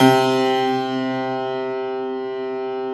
53e-pno06-C1.wav